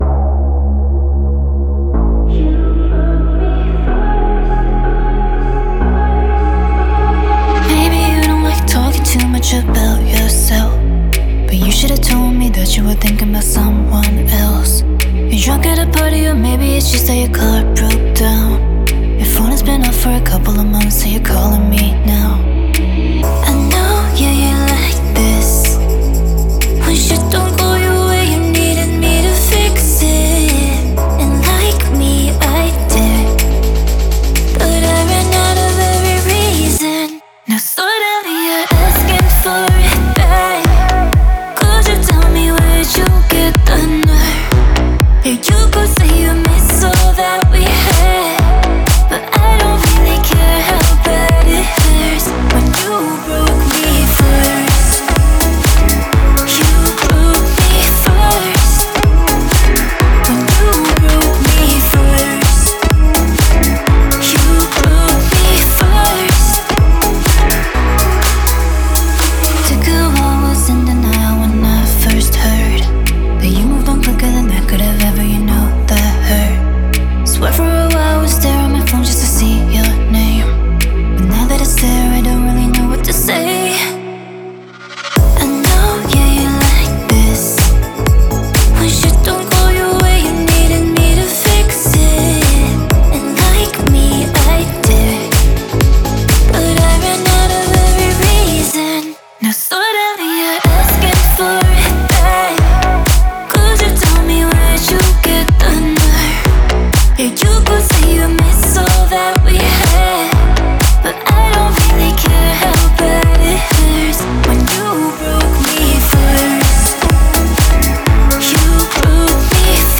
энергичная поп-песня